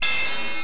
clash.mp3